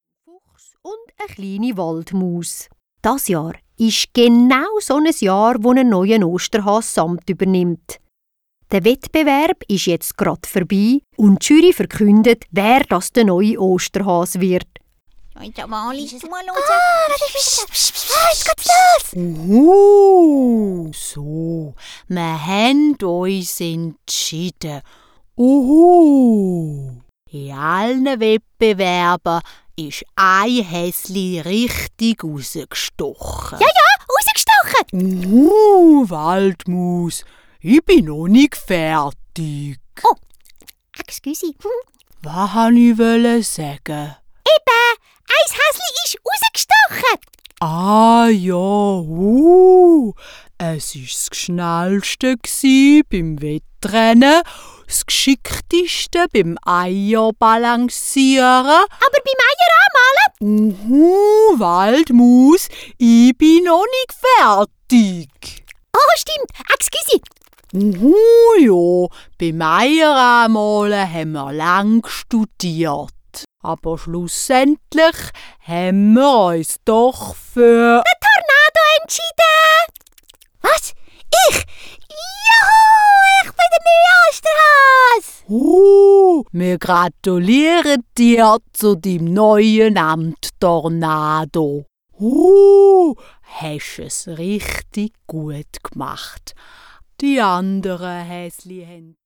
Dialekt Ostergeschichte